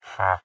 sounds / mob / villager / haggle3.ogg
haggle3.ogg